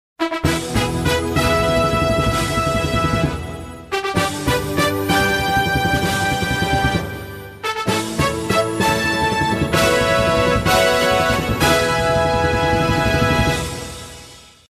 Звуки торжественной музыки
Торжественная фанфара праздника